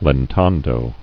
[len·tan·do]